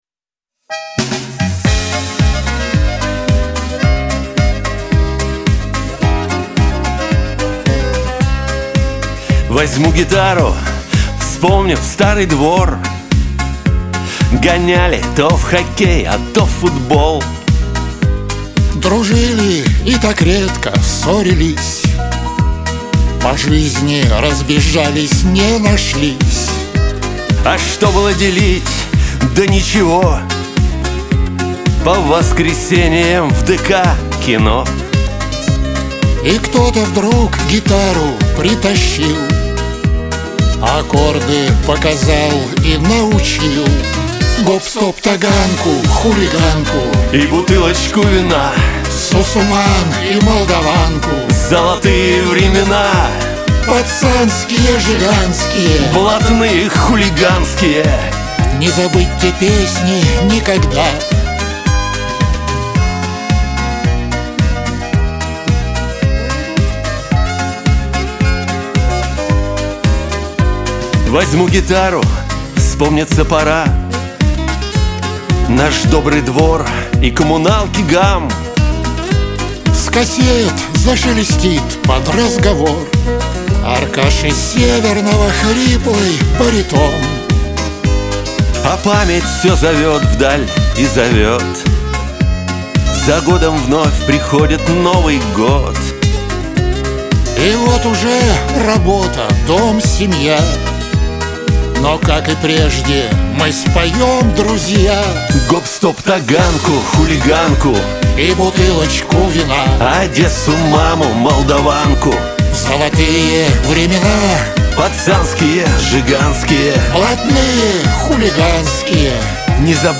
Лирика